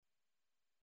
نشرة أخبار السابعة مساء ليوم الأربعاء 29 جويلية 2015